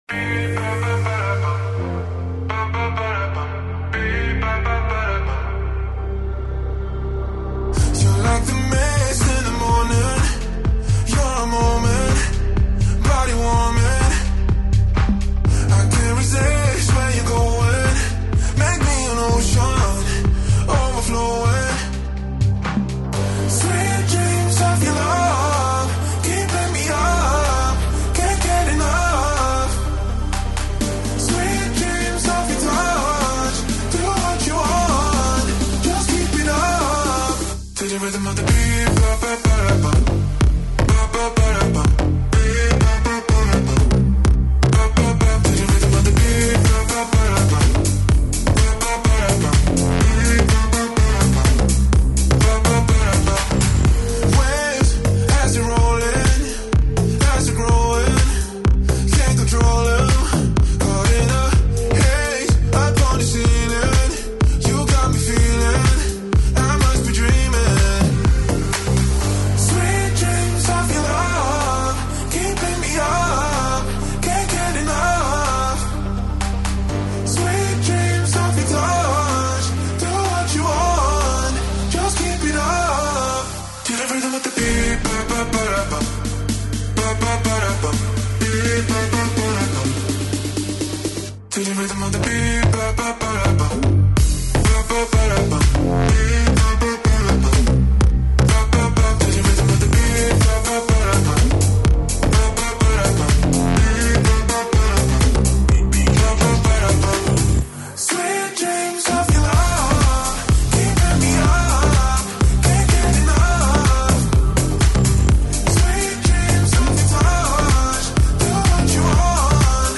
Dance-Eletronicas